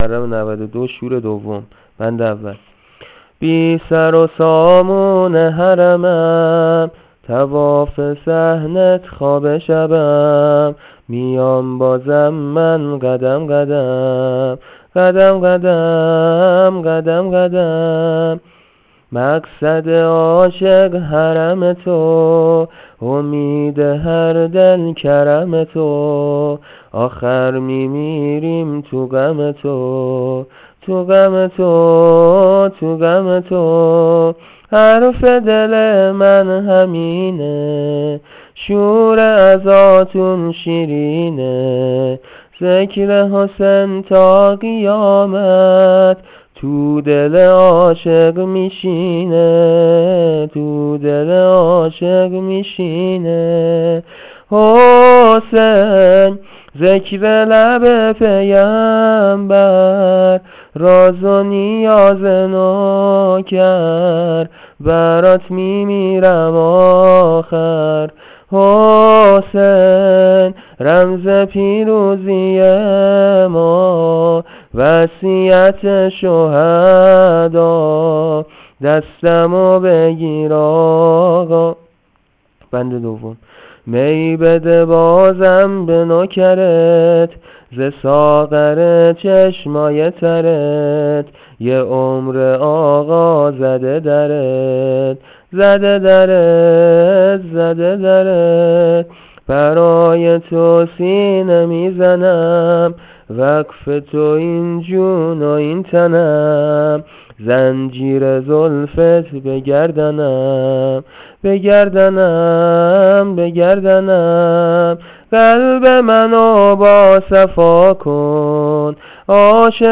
شور2.بی-سر-و-سامون-حرمم.wav